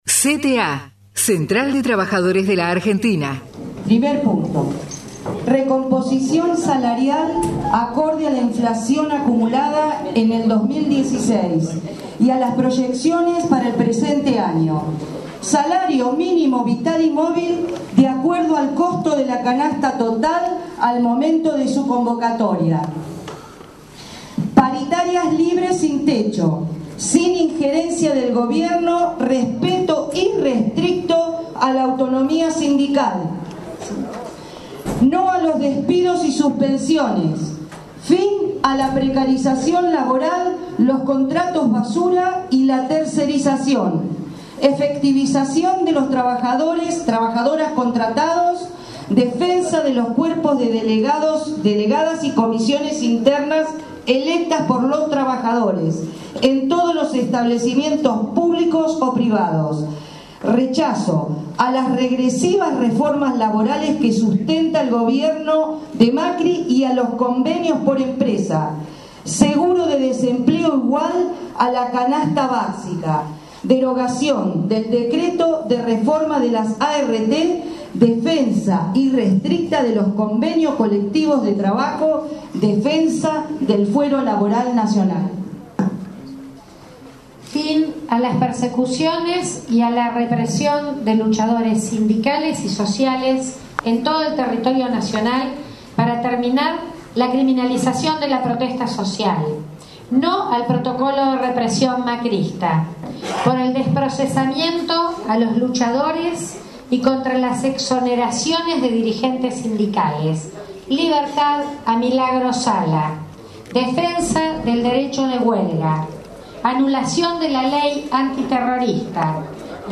PLENARIO NACIONAL DE AMBAS CTA // Parte 2